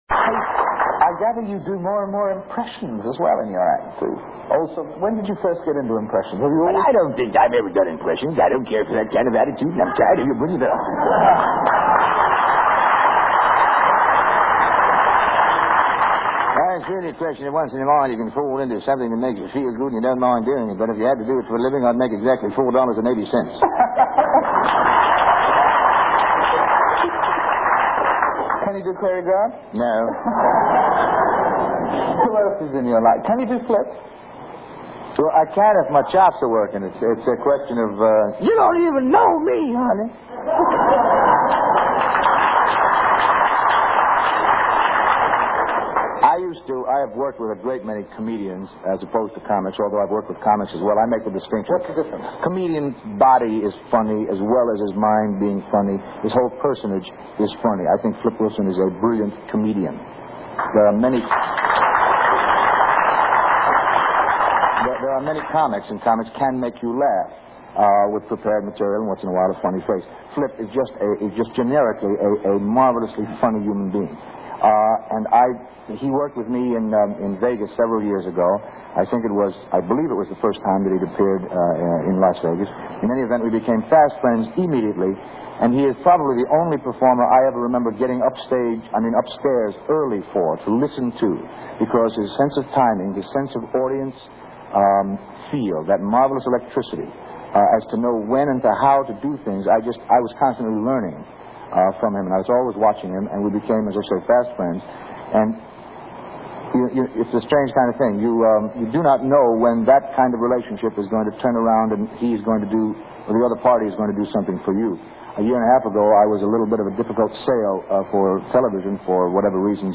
David Frost Interview with Bobby Darin
Bobby sings "Mack the Knife" to huge applause from Frost and the audience.